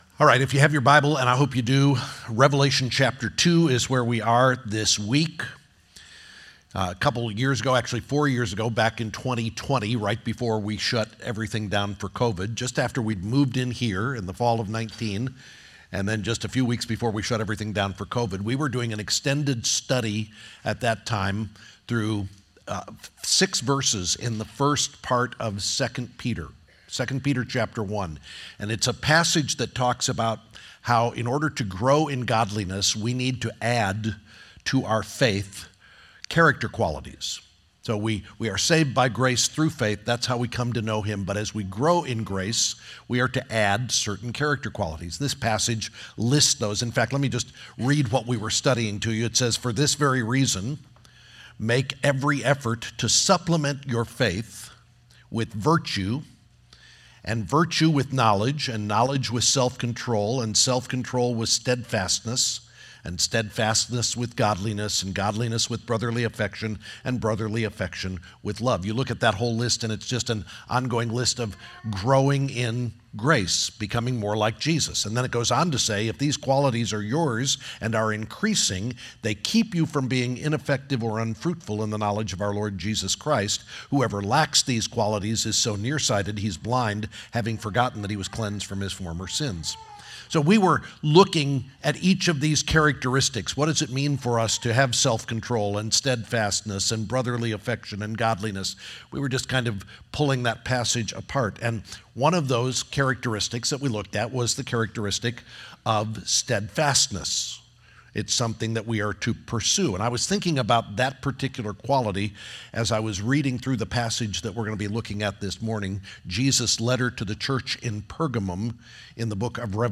The next sermon in our series through the book of Revelation looking at issues that affected churches in the first century and can affect churches today if they don’t see the need for daily repentance and faith in Christ and His promises.